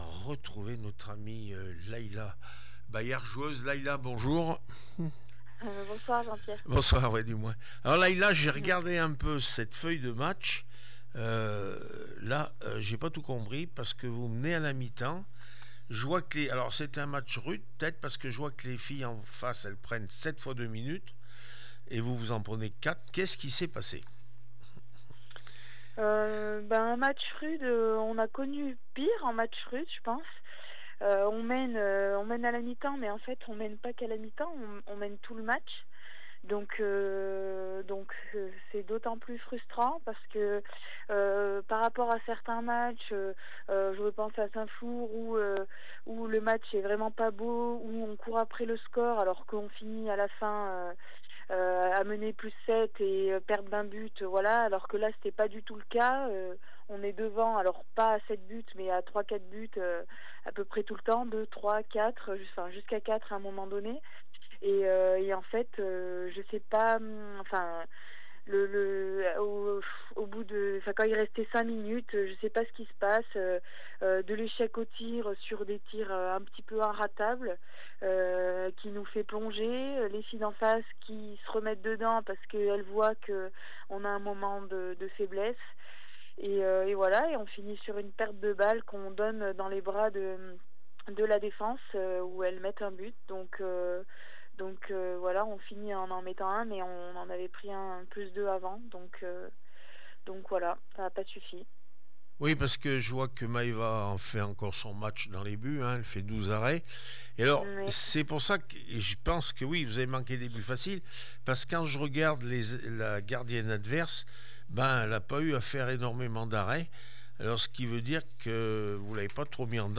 18 novembre 2025   1 - Sport, 1 - Vos interviews
handball feminin n2 st Flour 28-27 st germain Blavozy réaction après match